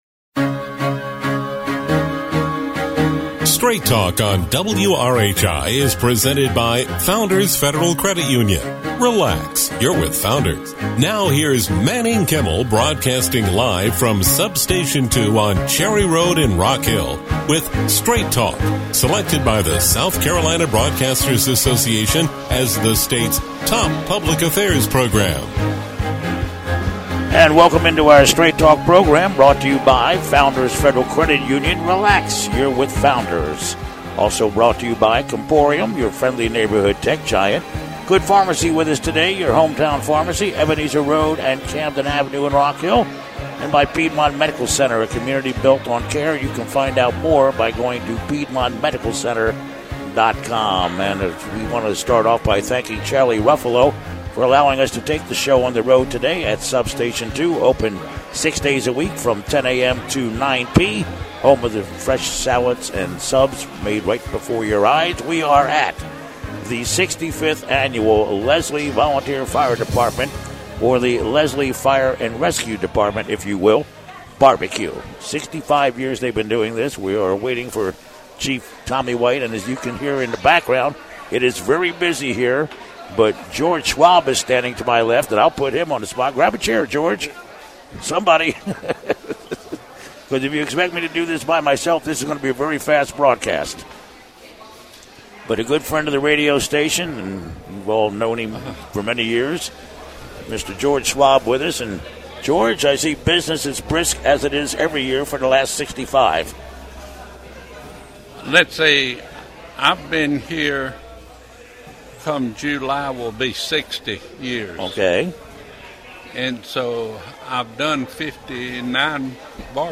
Live from the Lesslie Fire Department Annual BBQ